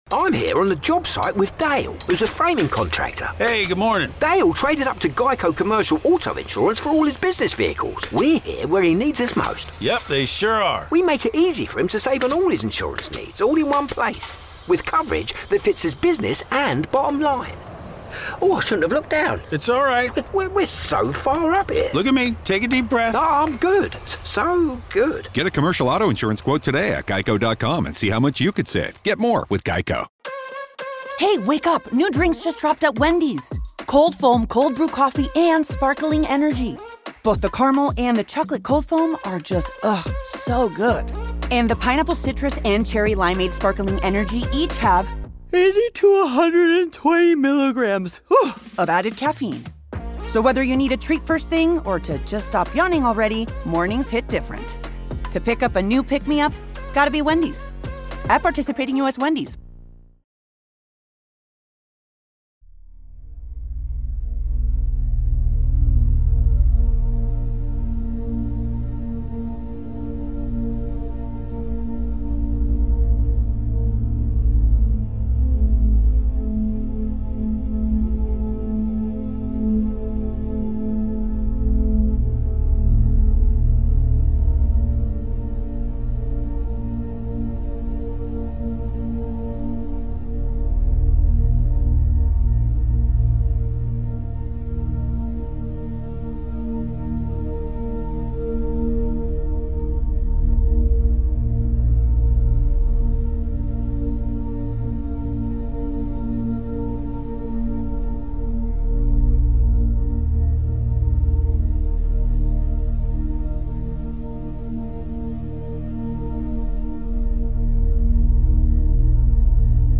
70hz - Gamma Binaural Beats for Mindfulness